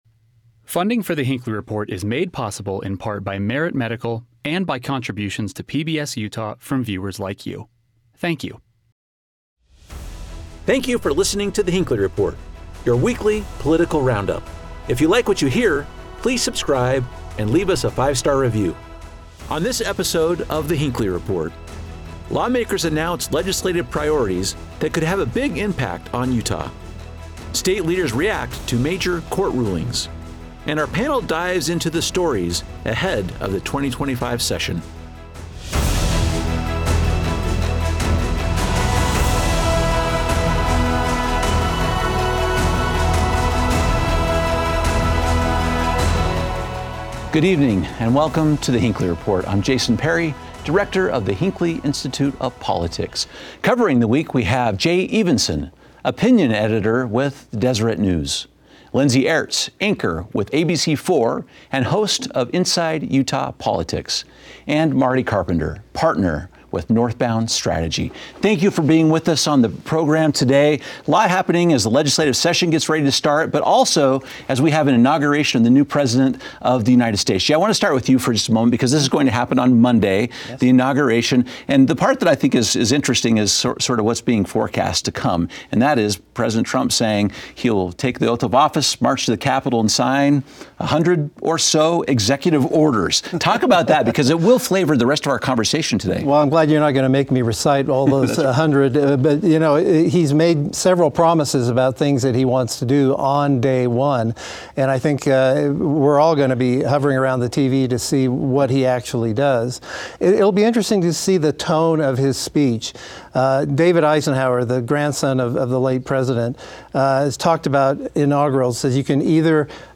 Our panel examines how Utah could be impacted, including potential changes to the boundaries of national monuments like Bears Ears and Grand Staircase-Escalante.